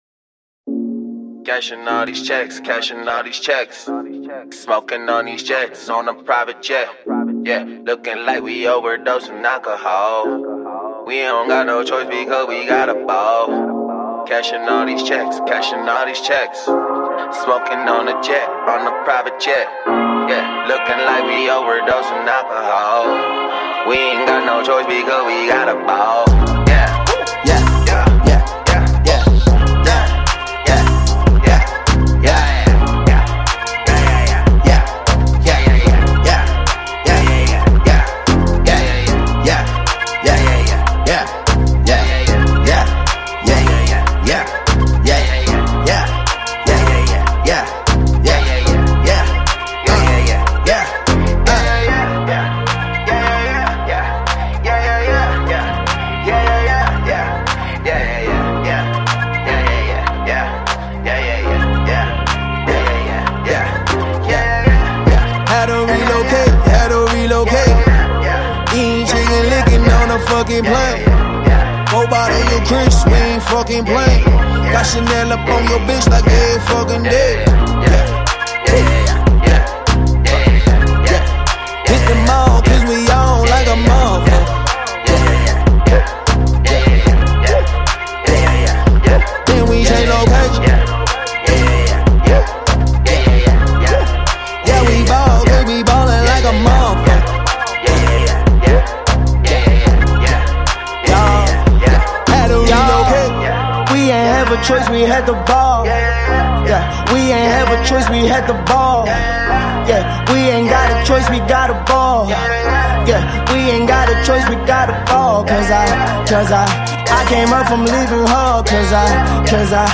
For the lovers of Hip Hop music